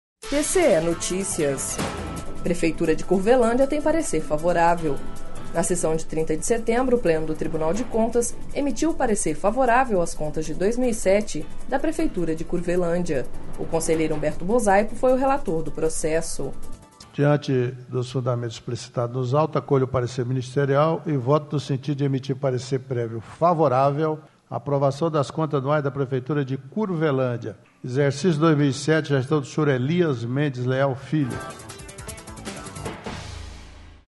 Na sessão de 30 de setembro, o Pleno do Tribunal de Contas emitiu parecer favorável às contas de 2007 da Prefeitura de Curvelândia./ O conselheiro Humberto Bosaipo foi o relator do processo.// Sonora: Humberto Bosaipo – conselheiro do TCE-MT